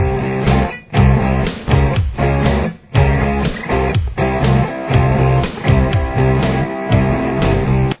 Verse Riff
The verse riff also has two guitar parts.